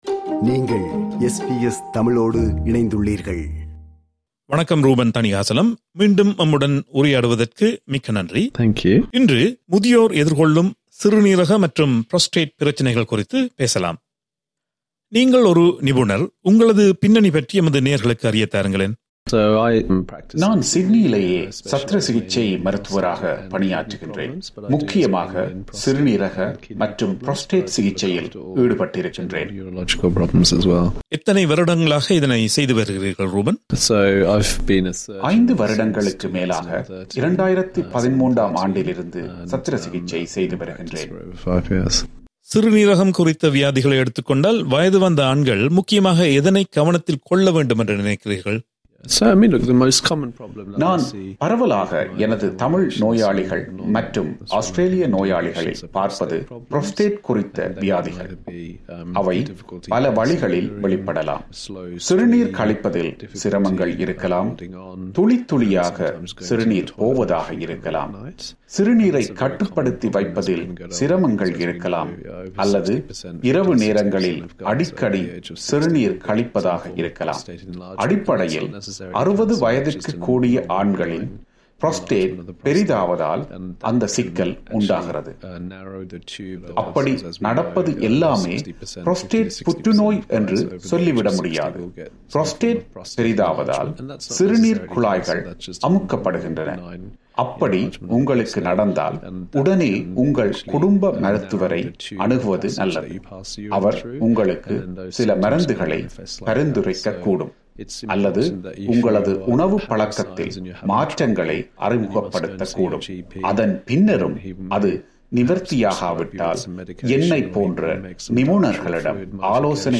2019ஆம் ஆண்டு ஒலிபரப்பான நேர்காணலின் மறு ஒலிபரப்பு இது.
This interview was initially broadcast in July 2019.